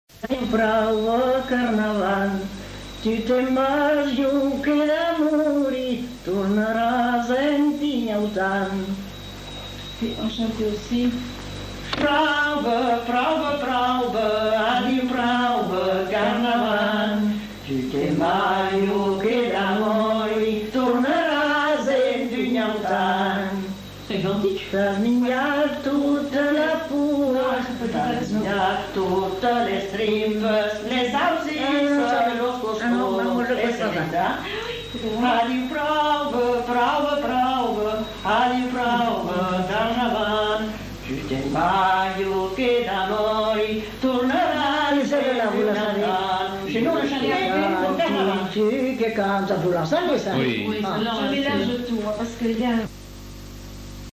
Lieu : Mont-de-Marsan
Genre : chant
Type de voix : voix de femme
Production du son : chanté
Classification : chanson de carnaval
Notes consultables : Deux versions sont chantées.